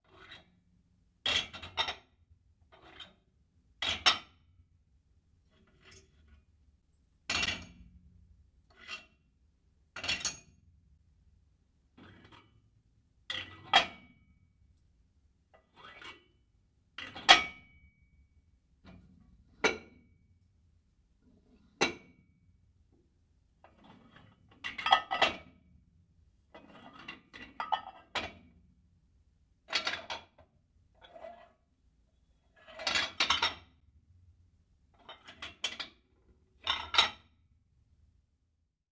Звук качающего мышцы бодибилдера со штангой в тяжелой атлетике